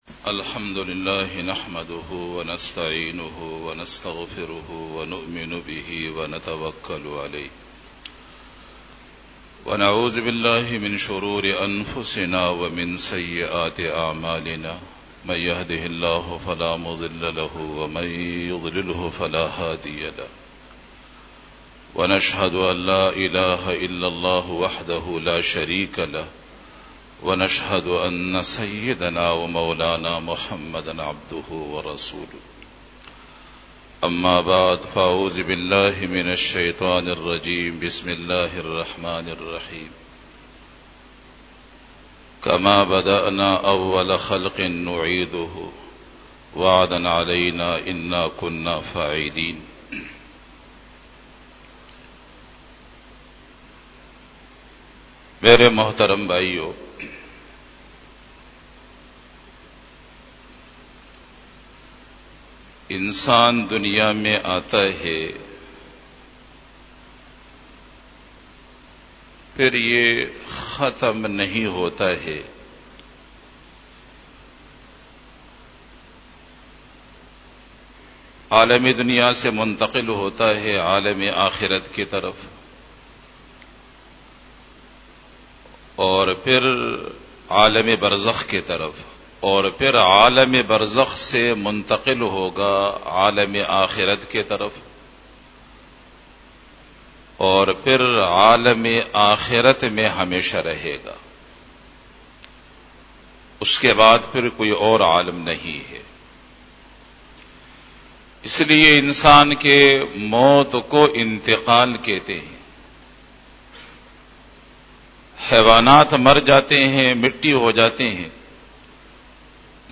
44 BAYAN E JUMA TUL MUBARAK (02 November 2018) (23 Safar 1440H)
Khitab-e-Jummah 2018